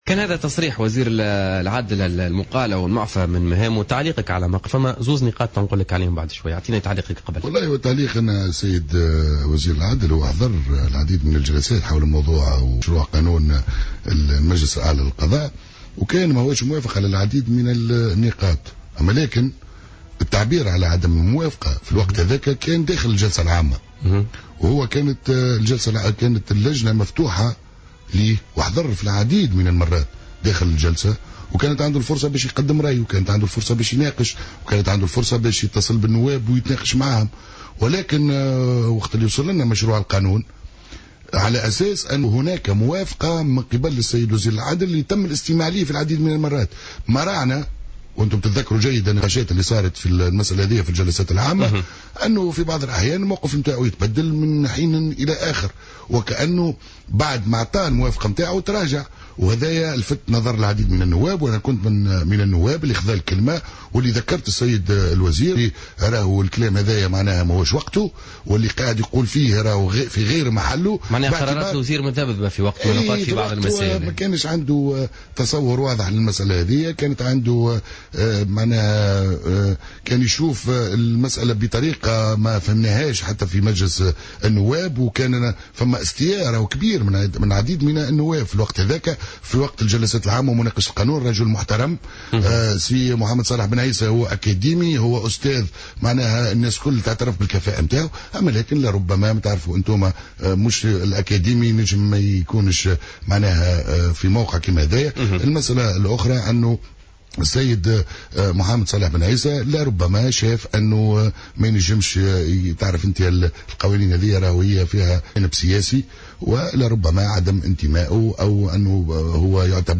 أكد عبد العزيز القطي القيادي في نداء تونس ضيف بوليتيكا اليوم الأربعاء 21 أكتوبر 2015 أن هناك حركية في الحكومة وعملية تقييم يقوم بها رئيس الحكومة لوزرائه وخاصة الذين لا يملكون تضامنا حكوميا في ما بينهم وفق قوله.